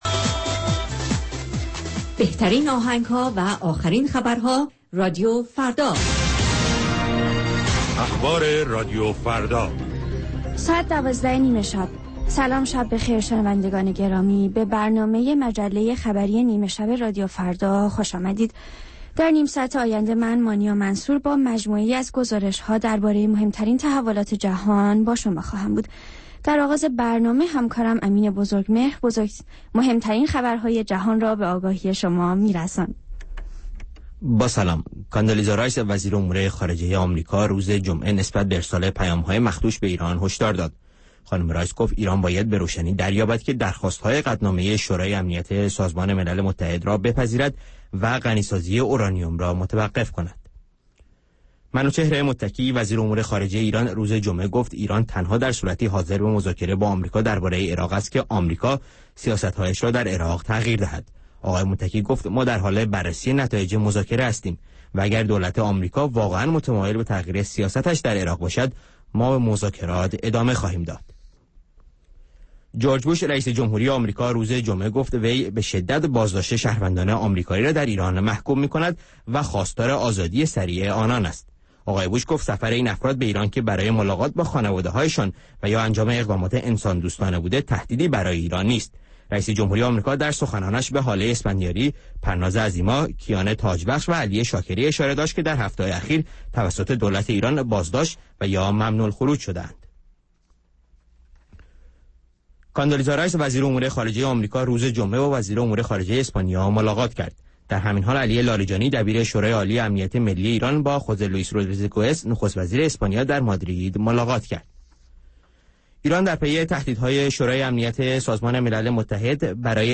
همچون هر روز، مجله نیمه شب رادیو فردا، تازه ترین خبر ها و مهم ترین گزارش ها را به گوش شما می رساند.